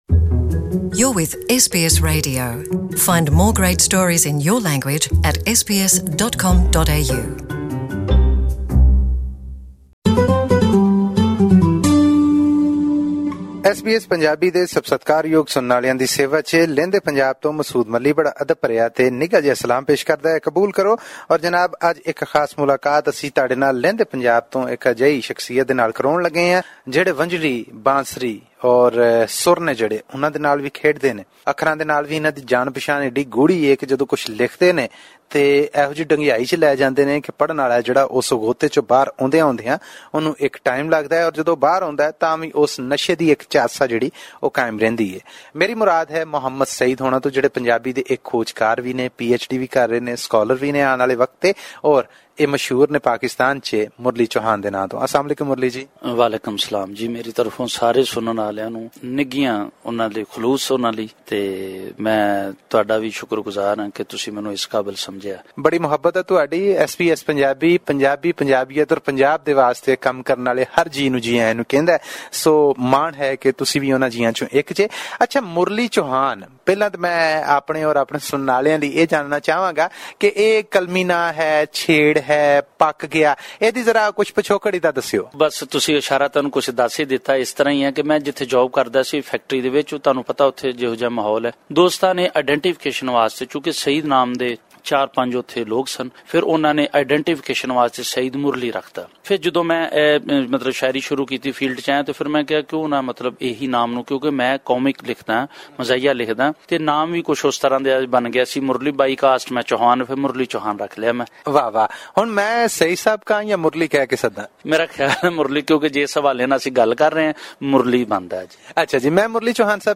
Listen to this interview in Punjabi by clicking on the player at the top of the page.